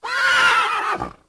horse.ogg